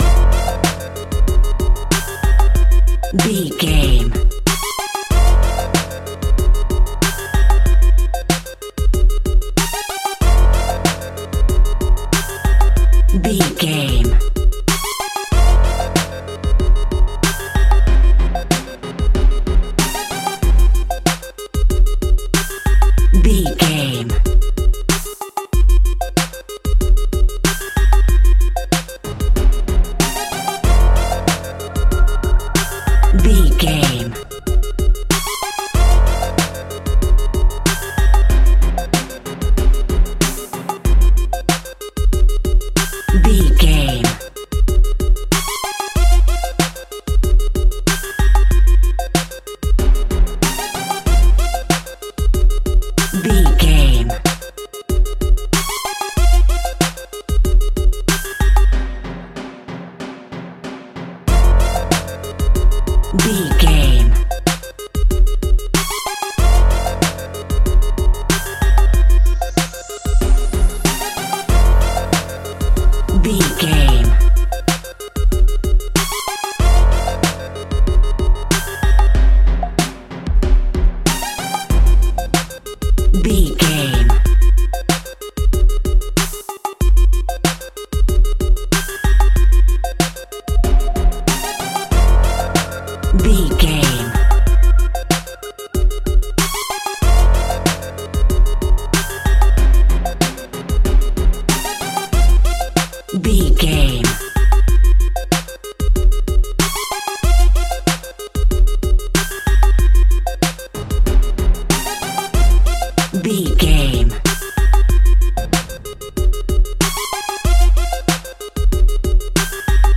Aeolian/Minor
drum machine
synthesiser
electric piano
funky
hard hitting